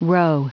Prononciation du mot roe en anglais (fichier audio)
Prononciation du mot : roe